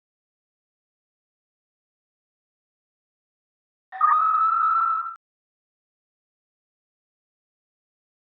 TahoeScream.mp3